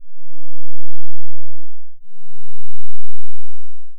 you can try with the factory waves, but the lowest one in there C2 even pitched down 2 octaves (which is max) is still fast, you can generate your own single cycle lfo waves and just use them, like this one for example:
loop it, pitch it up and it will give you sine-ish lfo